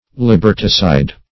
Search Result for " liberticide" : The Collaborative International Dictionary of English v.0.48: Liberticide \Lib"er*ti*cide\ (l[i^]b"[~e]r*t[i^]*s[imac]d), n. [L. libertas liberty + caedere to kill: cf. (for sense 2) F. liberticide.] 1.